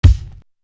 Intrude Kick.wav